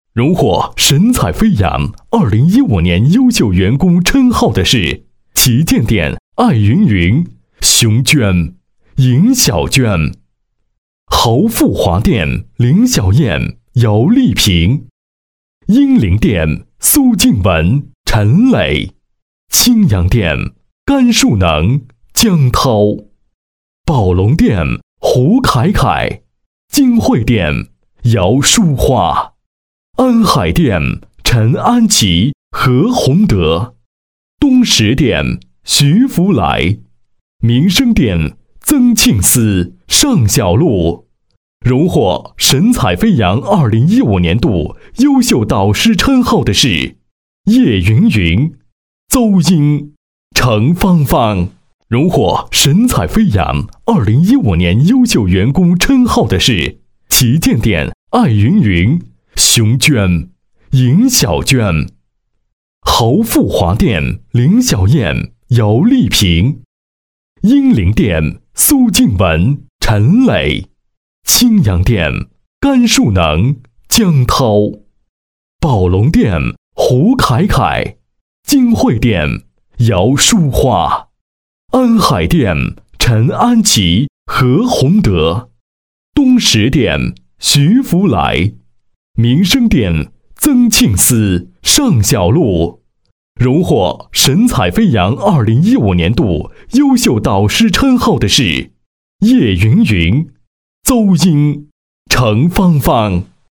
国语中年激情激昂 、大气浑厚磁性 、沉稳 、男广告 、300元/条男S339 国语 男声 广告-房地产广告-恢宏大气 激情激昂|大气浑厚磁性|沉稳